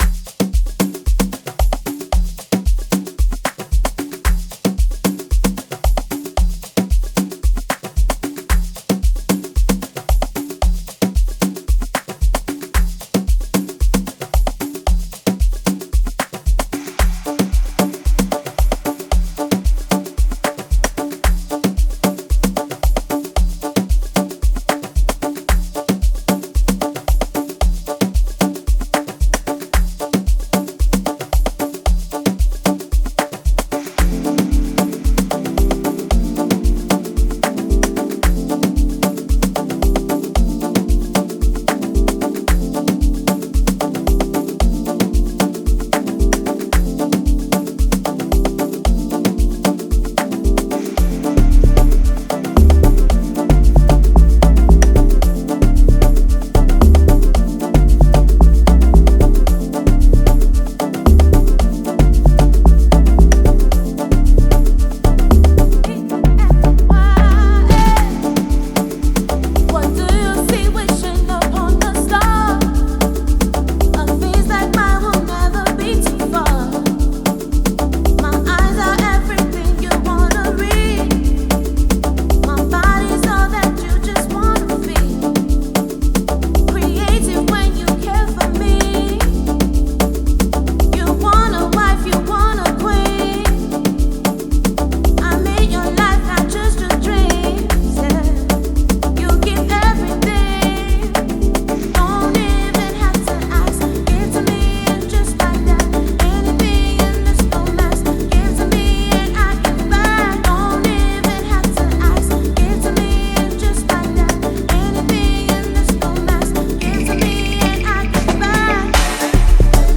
Ghanaian singer
amapiano influenced record